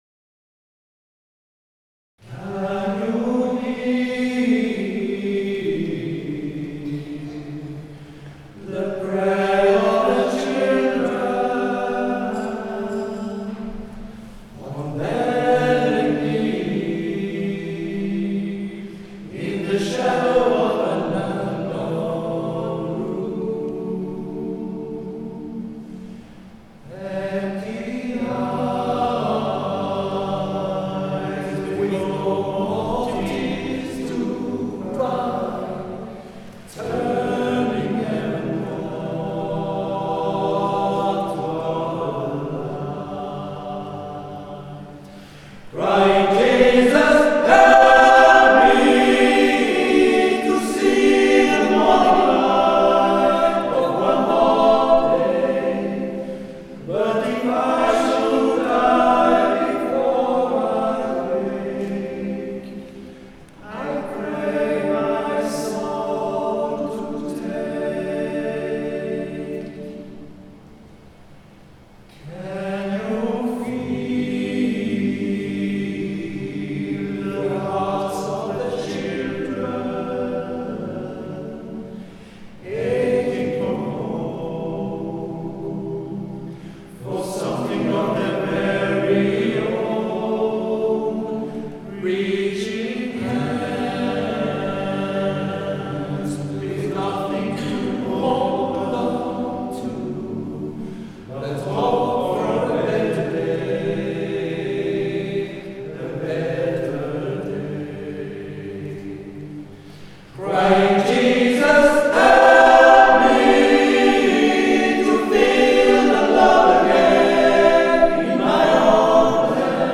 Groupe Vocal Masculin de 8 chanteurs, Choeur d'hommes
Ensemble vocal masculin